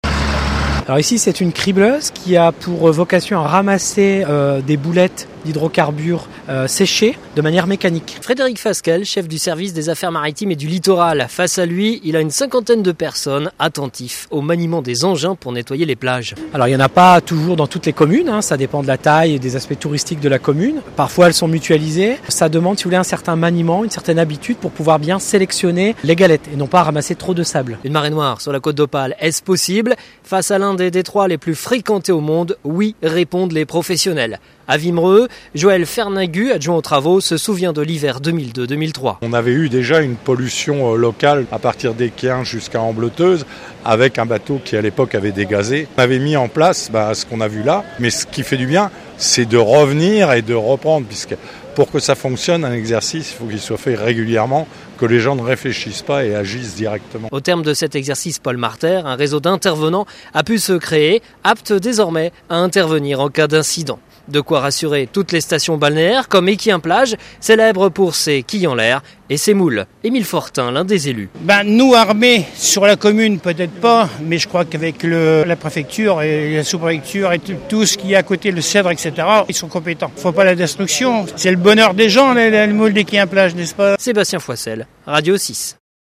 La côte d'opale prête à lutter contre une marée noire ? un exercice grandeur nature a eu lieu jeudi sur la plage du Portel